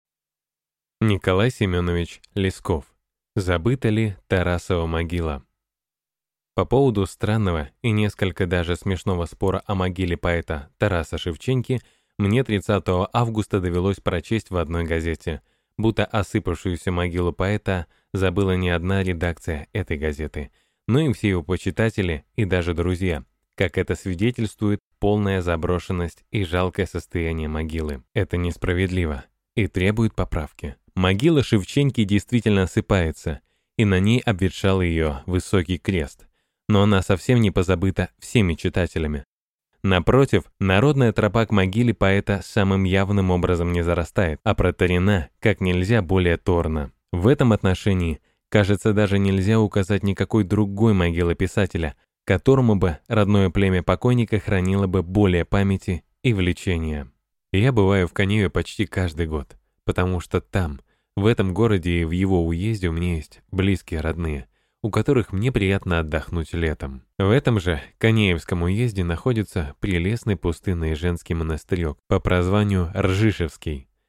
Аудиокнига Забыта ли Тарасова могила?